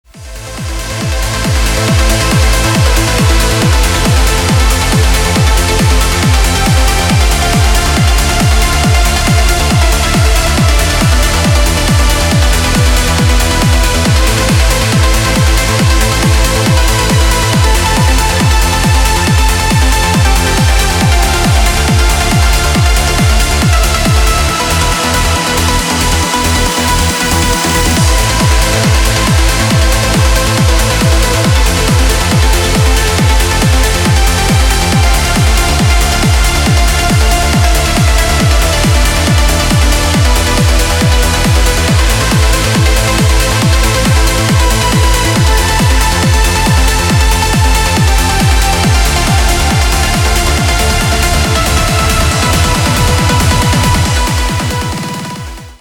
Electronic
EDM
без слов
Trance
Uplifting trance
Epic Trance
Красивая транс-композиция.